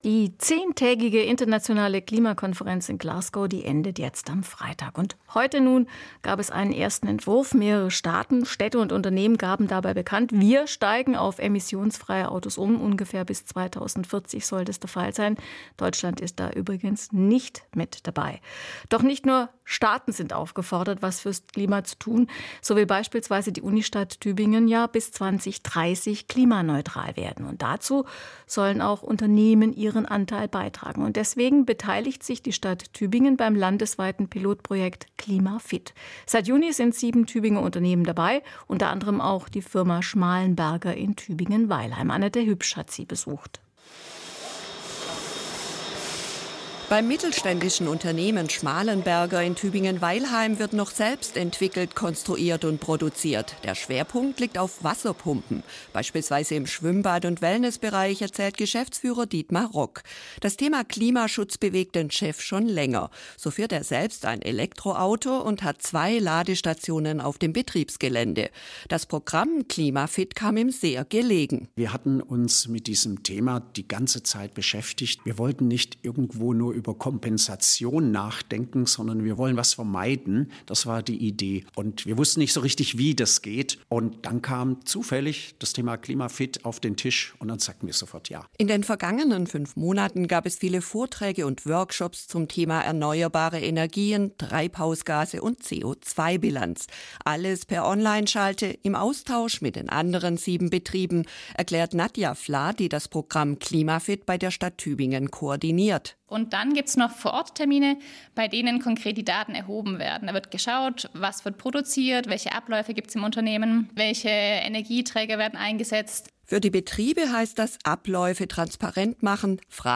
Zum SWR4 Radio Tübingen Beitrag >>